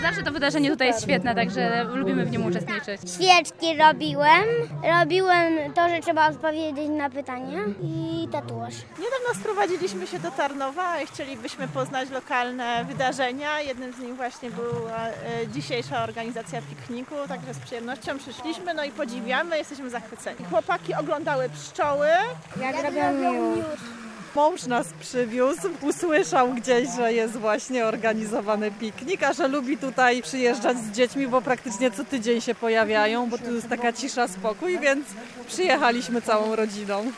Uczestnicy wydarzenia, z którymi rozmawialiśmy zgodnie przyznawali, że dobrze się bawią.